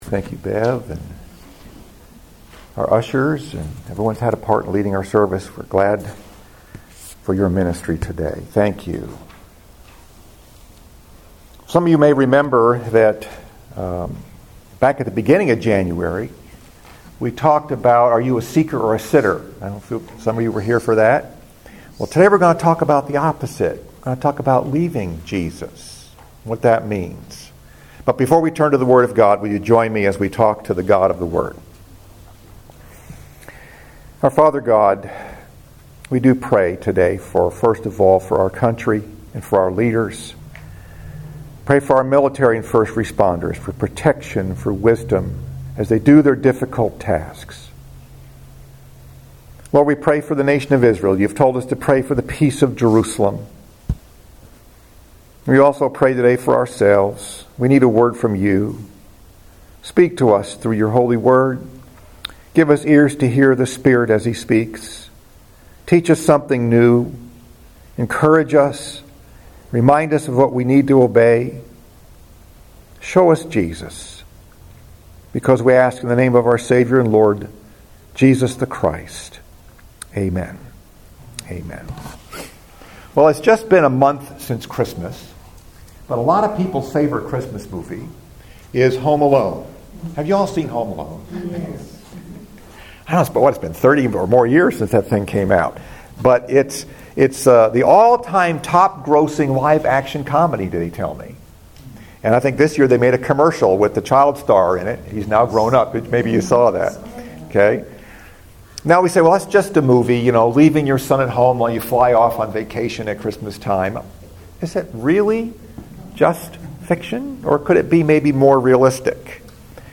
Message: “Leaving Jesus” Scripture: Luke 2:39-52 FIFTH SUNDAY AFTER CHRISTMAS